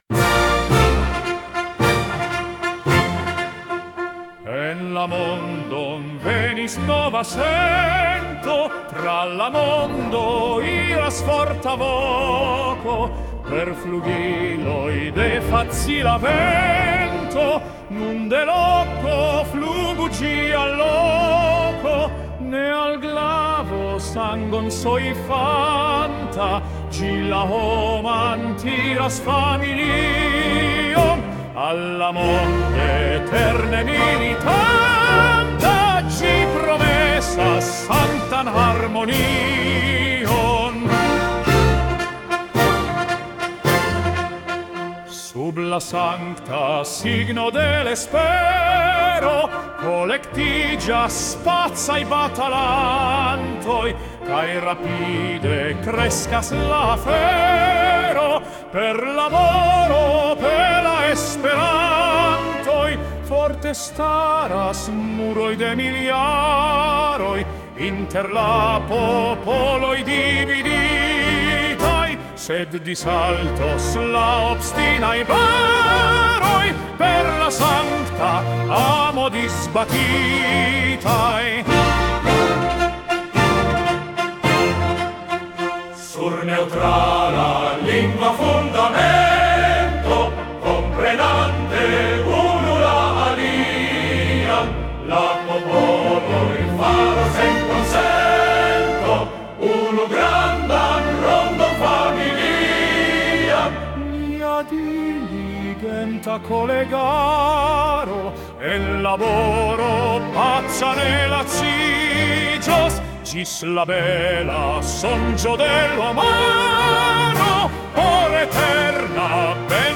la-espero-viro.mp3